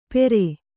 ♪ pere